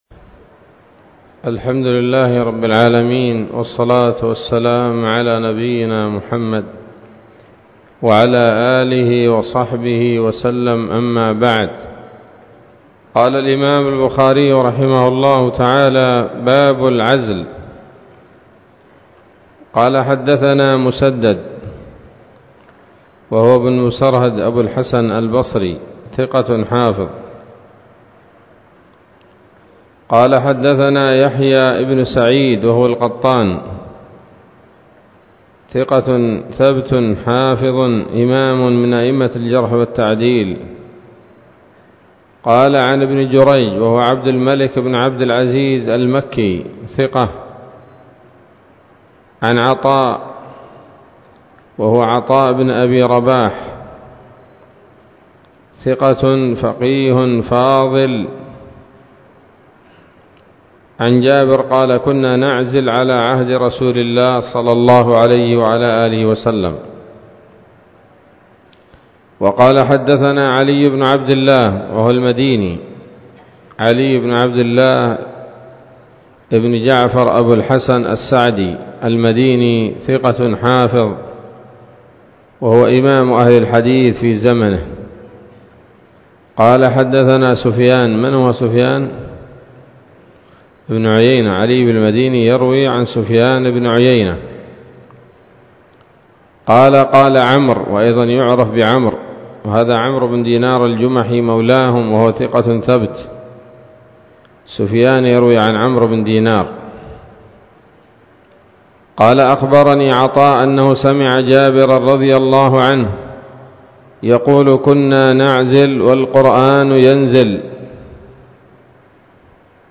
الدرس الثامن والسبعون من كتاب النكاح من صحيح الإمام البخاري